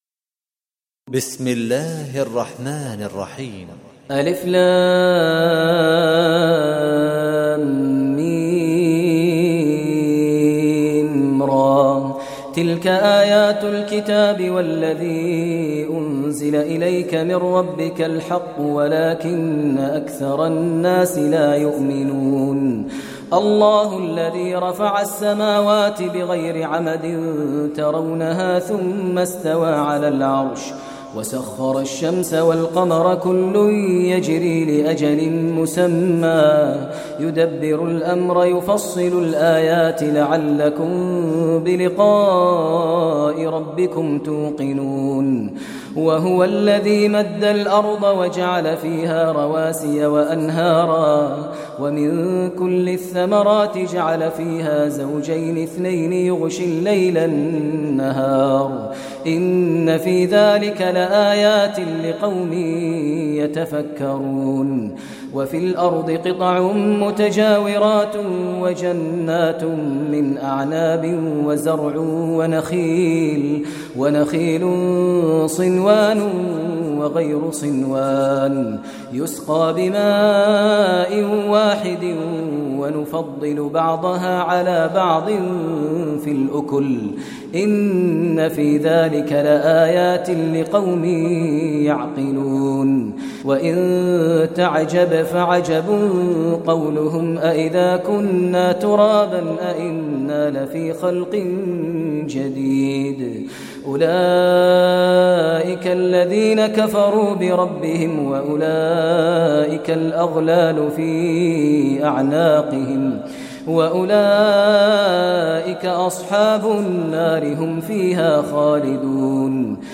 Surah Ar Raad Recitation by Maher Mueaqly
Surah Ar Raad, listen online mp3 tilawat / recitation in Arabic, recited by Imam e Kaaba Sheikh Maher al Mueaqly.